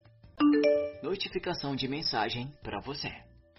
Som de notificação do Sasuke
Categoria: Toques
som-de-notificacao-do-sasuke-pt-www_tiengdong_com.mp3